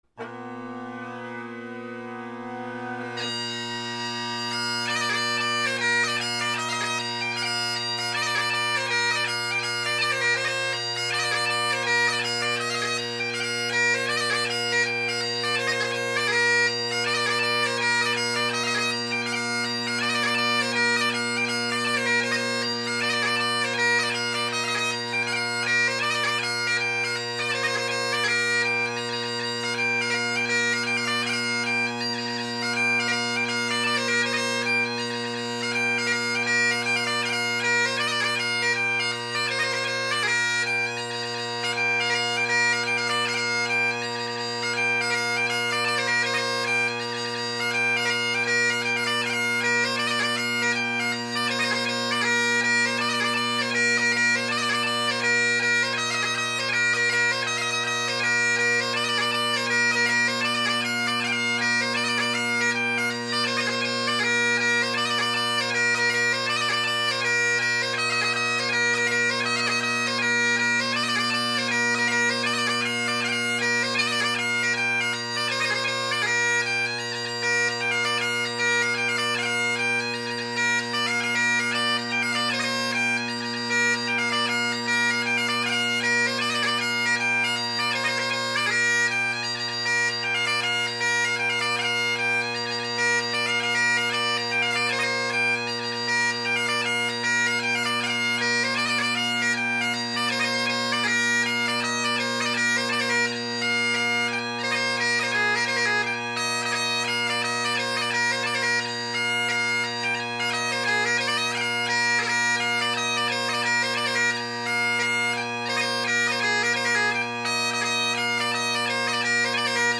Great Highland Bagpipe Solo, Tune of the Month
The Redundancy, The Clachnacuddin Hornpipe, Rakes of Kildare, and Donella Beaton – a HHJJ because why not?
Colin Kyo with full Ezeedrone reeds and an old beat up Gilmour chanter reed in an older CK chanter.